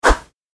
戦闘 （163件）
振り回す2.mp3